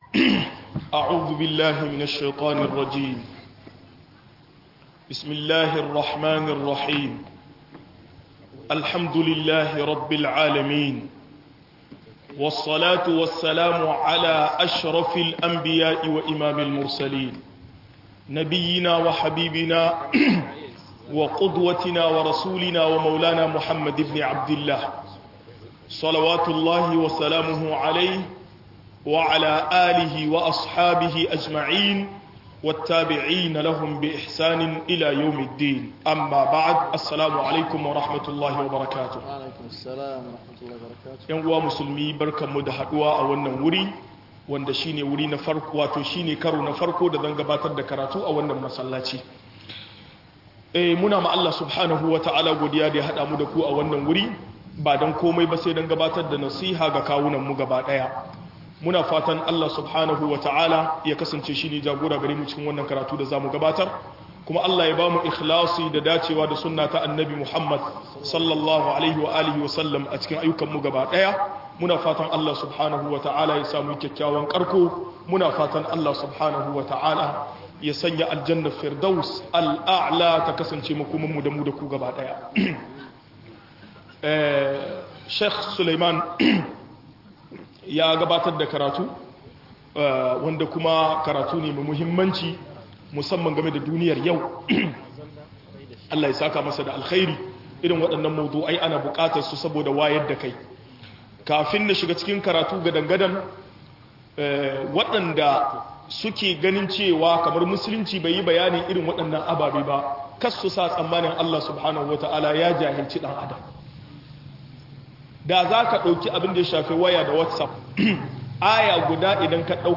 Wasu kurarai a cikin sallah - MUHADARA